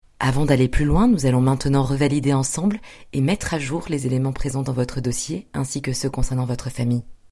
Voix off (formation)
- Basse